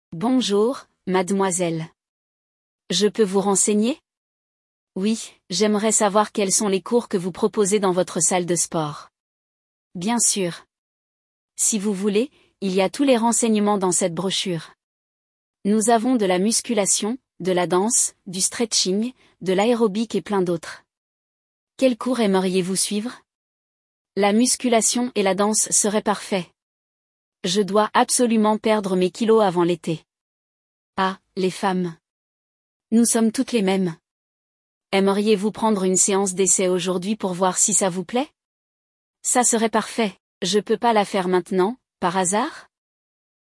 LE DIALOGUE